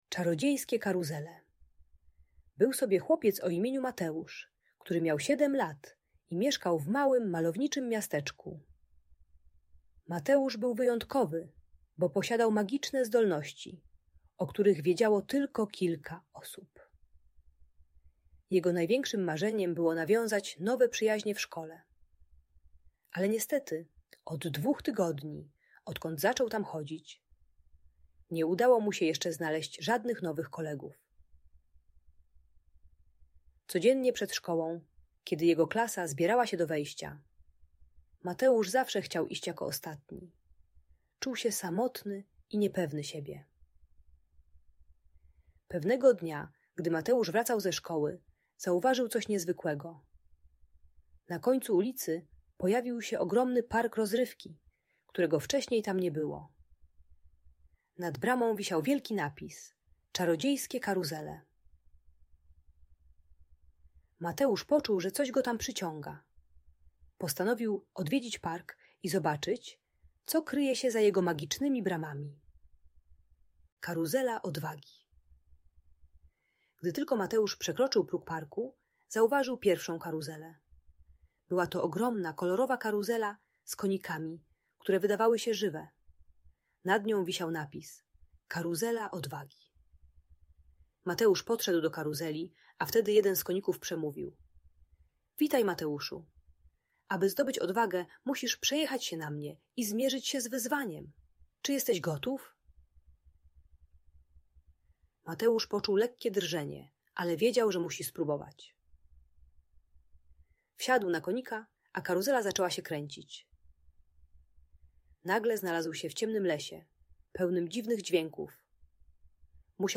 Czarodziejskie Karuzele - Magiczna Story o Odwadze i Przyjaźni - Audiobajka